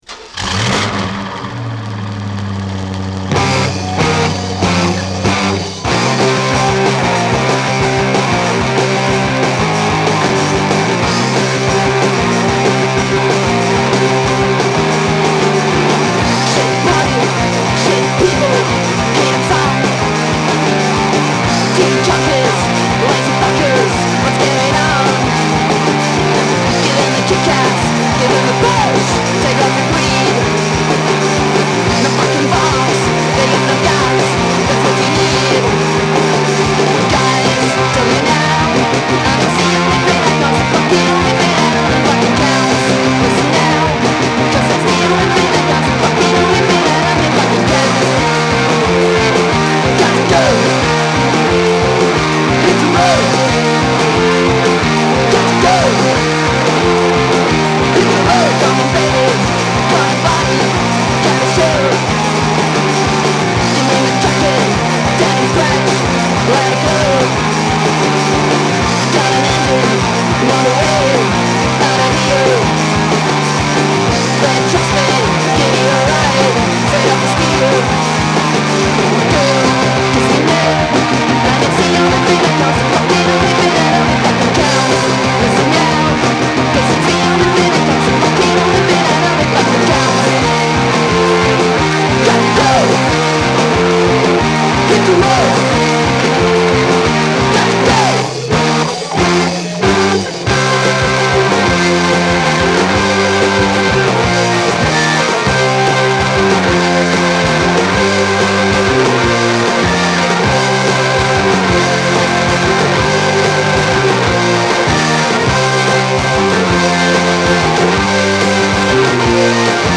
Sorry about the crackles...